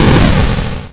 ihob/Assets/Extensions/explosionsoundslite/sounds/bakuhatu131.wav at master
bakuhatu131.wav